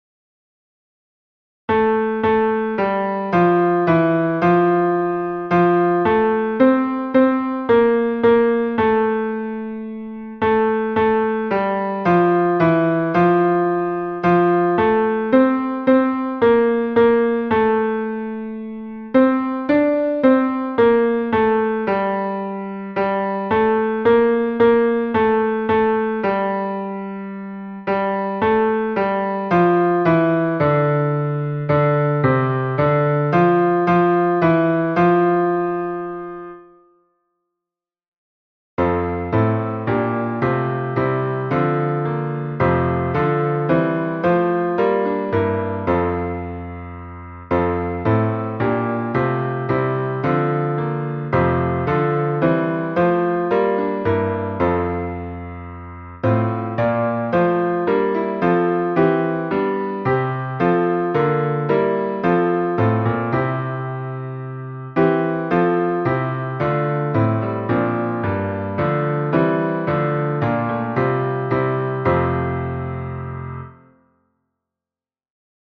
MP3 version piano
Basse